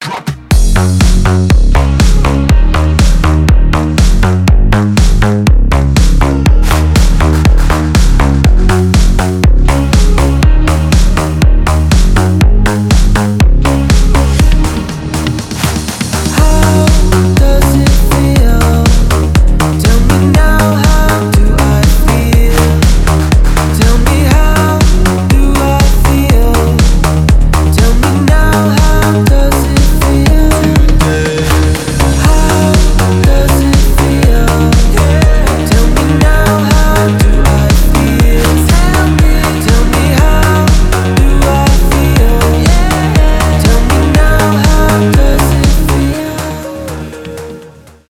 танцевальные
house , retromix